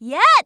Shua_voice_smash.wav